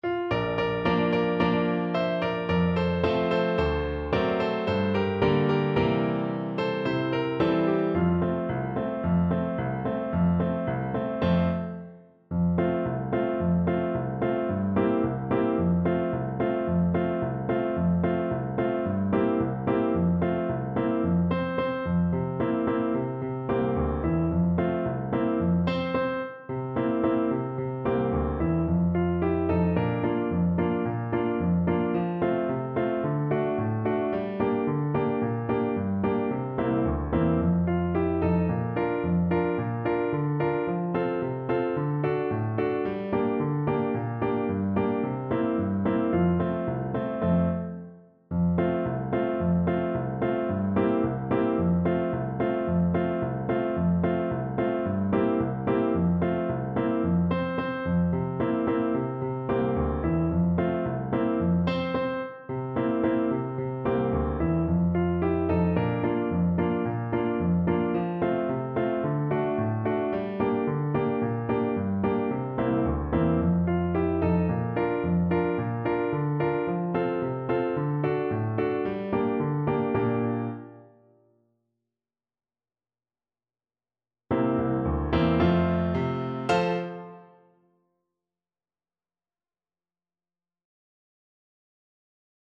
Play (or use space bar on your keyboard) Pause Music Playalong - Piano Accompaniment Playalong Band Accompaniment not yet available transpose reset tempo print settings full screen
French Horn
F major (Sounding Pitch) C major (French Horn in F) (View more F major Music for French Horn )
2/4 (View more 2/4 Music)
Allegro =c.110 (View more music marked Allegro)
C4-F5
Classical (View more Classical French Horn Music)